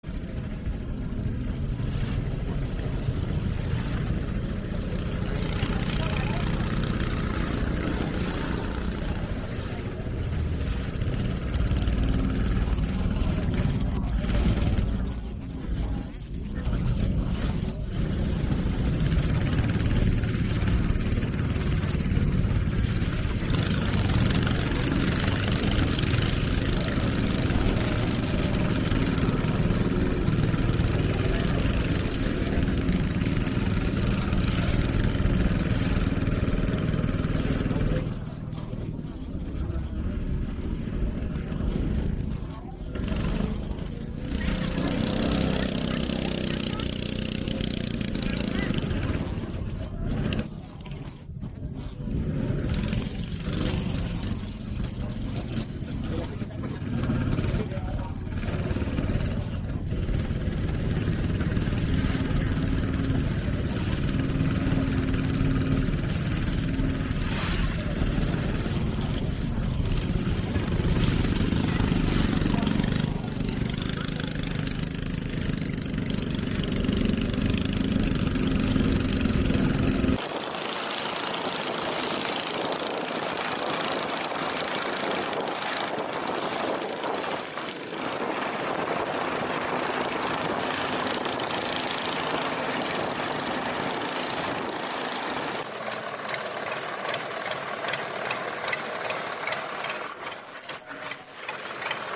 Tiếng ồn ào miền sông nước, miền Tây, chợ nổi…
Thể loại: Tiếng xe cộ
Description: Tiếng máy ghe nổ “phành phạch”, tiếng chợ nổi, tiếng sinh hoạt miền sông nước, tiếng buôn bán trên sông, tiếng ghe thuyền tấp nập, tiếng rao hàng vang vọng trên sông, tiếng nước vỗ mạn thuyền, xen lẫn tiếng nói cười tấp nập của thương hồ. Âm thanh giàu chất đời thường, gợi lên không khí nhộn nhịp, thân quen của vùng sông nước Nam Bộ.
tieng-on-ao-mien-song-nuoc-mien-tay-cho-noi-www_tiengdong_com.mp3